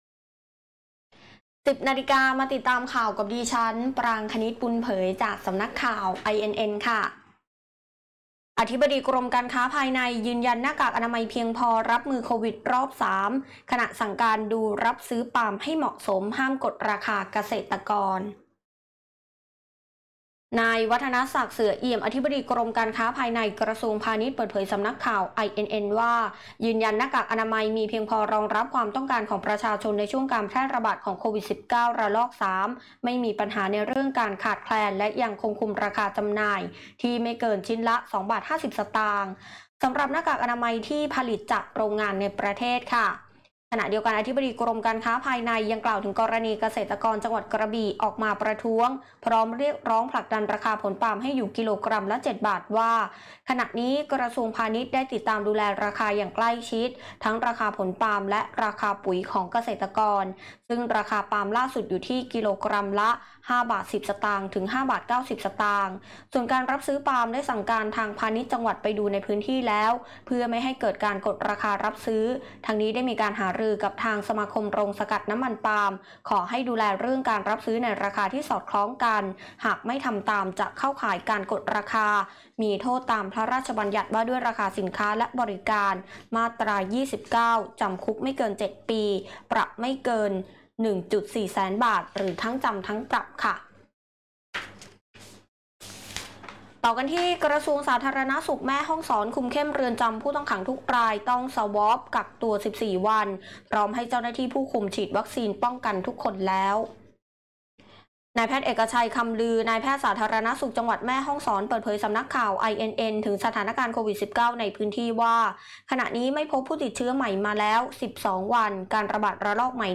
คลิปข่าวต้นชั่วโมง
ข่าวต้นชั่วโมง 10.00 น.